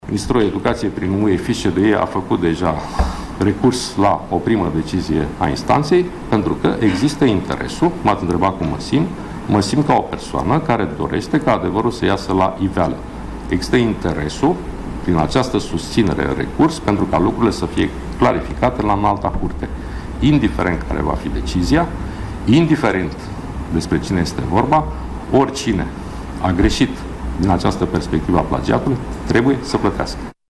Declarația fost difuzată de TVR.
Ministrul Educației, Sorin Cîmpeanu: „Există interesul, prin această susținere în recurs, ca lucrurile să fie clarificate la Înalta Curte. Mă simt ca o persoană care dorește ca adevărul să iasă la iveală